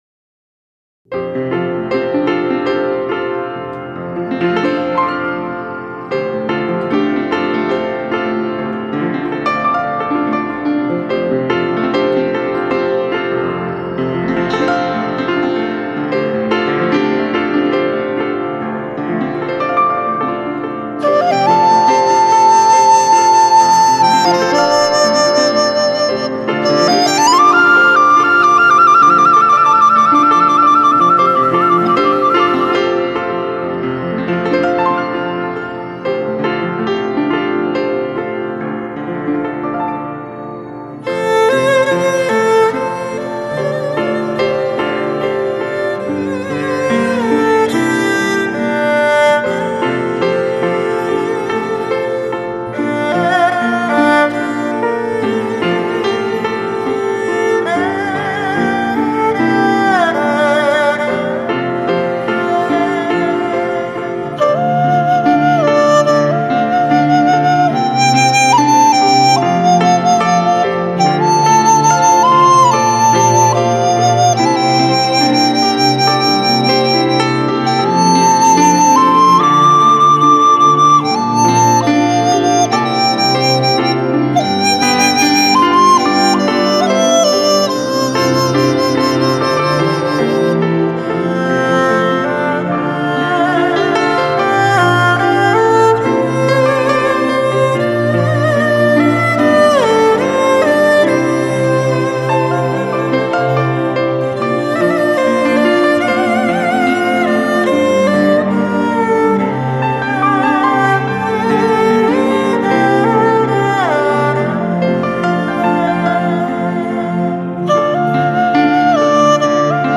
二胡
笛、笙、箫
古筝
吉他
钢琴
录音室：高雄亚洲数位录音室、影宸音乐工作室